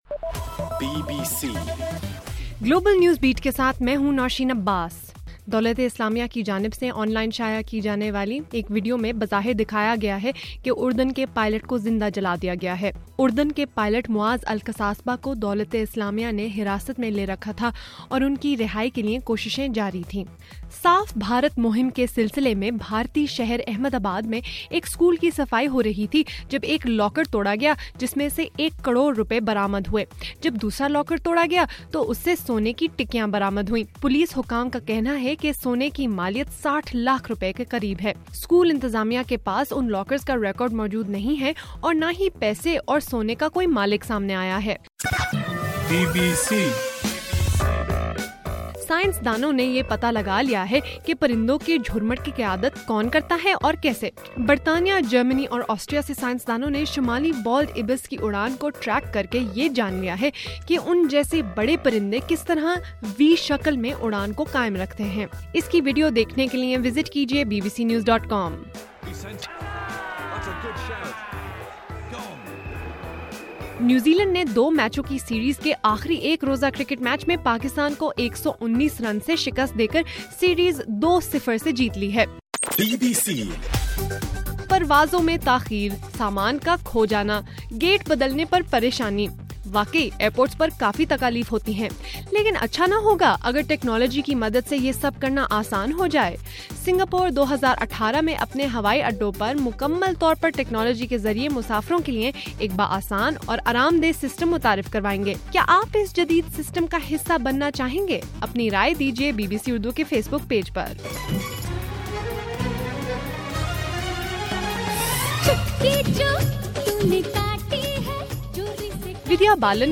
فروری 4: صبح 1 بجے کا گلوبل نیوز بیٹ بُلیٹن